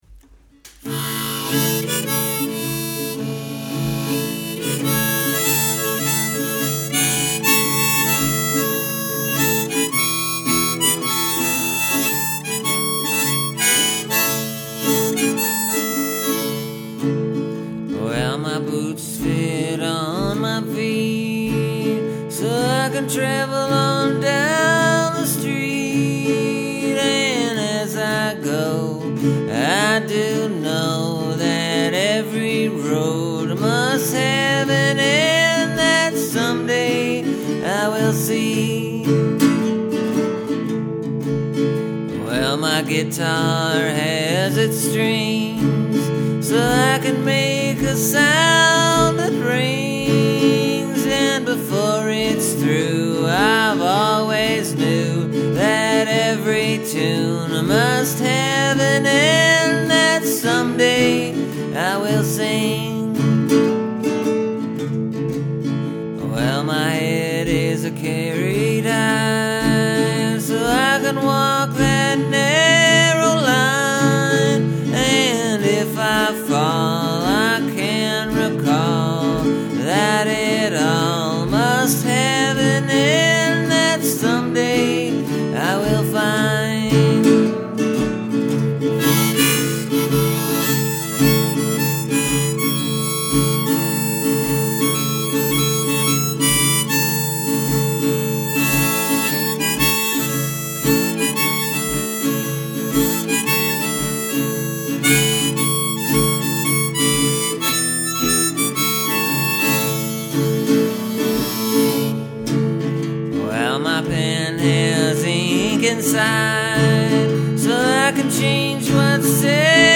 Plus, like that one, it’s a pretty straight-forward, old-fashioned folk tune, so-to-speak.